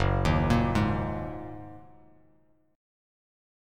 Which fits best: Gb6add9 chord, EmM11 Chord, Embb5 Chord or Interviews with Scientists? Gb6add9 chord